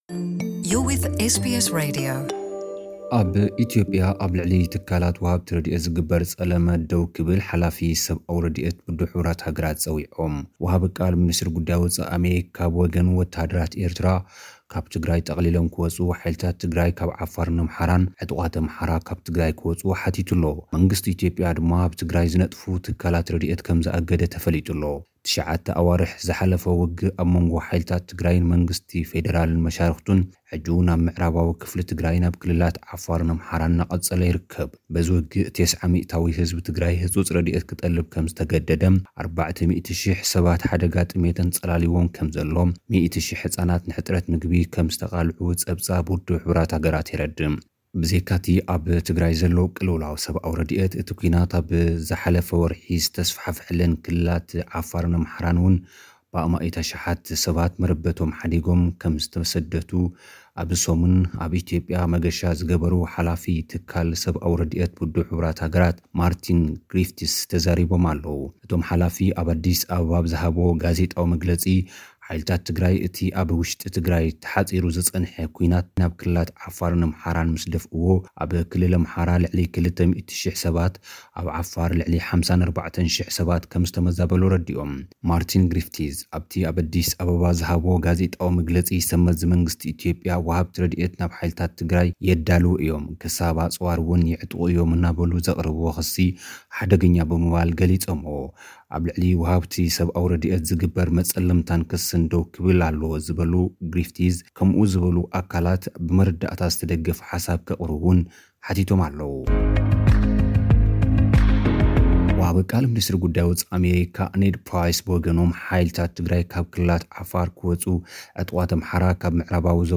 ሓጸርቲ ጸብጻብ፥